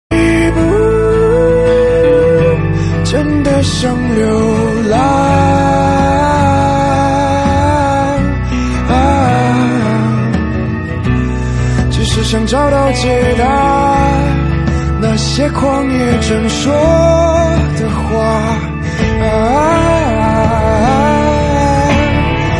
M4R铃声, MP3铃声, 华语歌曲 75 首发日期：2018-05-15 03:55 星期二